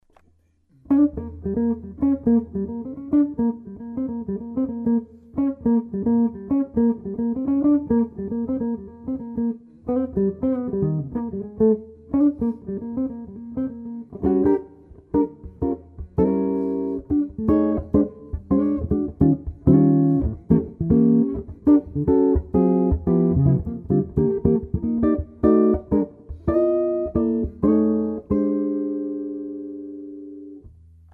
Jazz, Blues
ai_ten2_jazz_blues.mp3